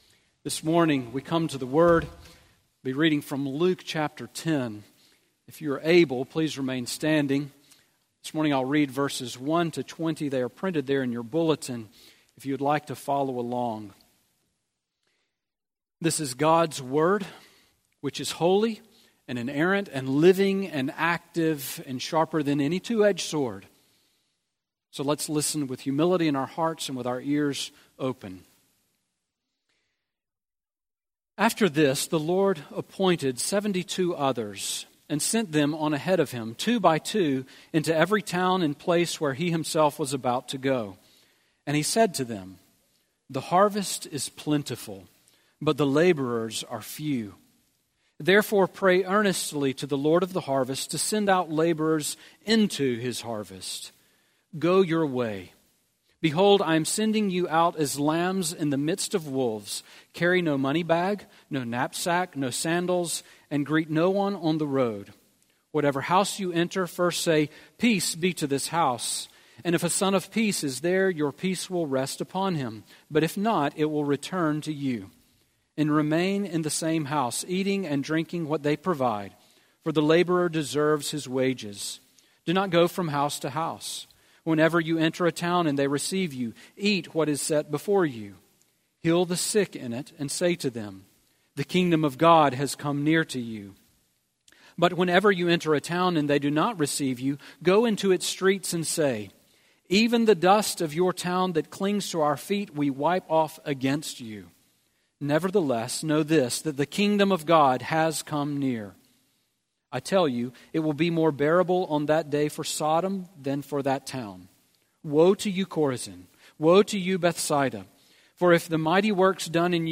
Sermon Audio from Sunday
Sermon on Luke 10:1-20 from January 28